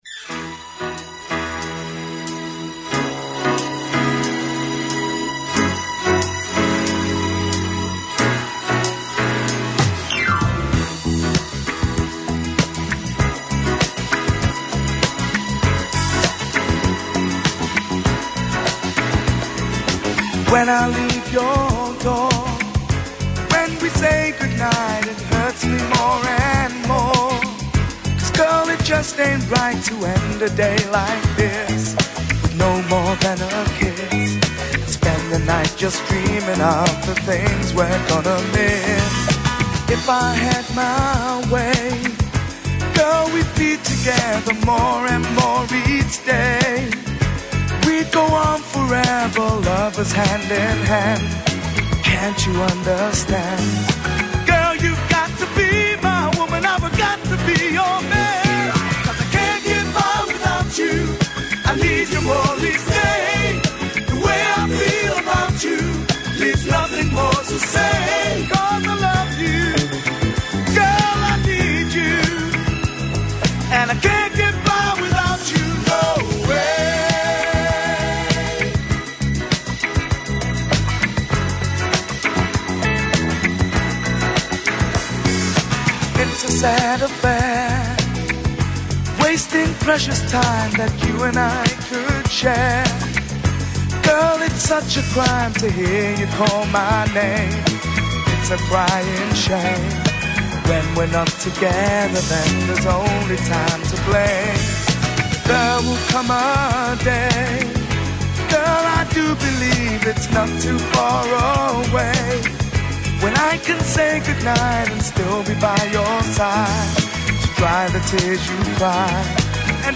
Ακούστε το σχόλιο του Νίκου Χατζηνικολάου στον RealFm 97,8, την Τετάρτη 29 Μαΐου 2024.